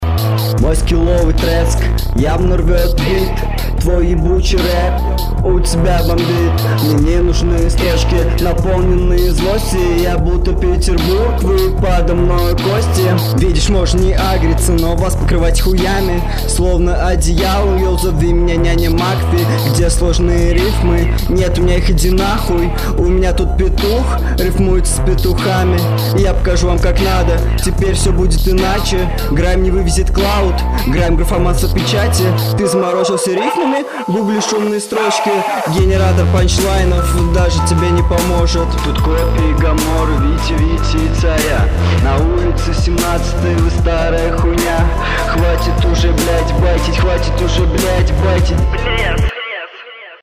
Средненький трек, текст не проработал под бит